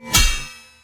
🌲 / foundry13data Data modules soundfxlibrary Combat Single Melee Hit
melee-hit-8.mp3